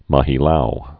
(mə-hē-lou, mä-hē-lyou) or Mo·gi·lev (mŏgə-lĕf, mə-gĭ-lyôf)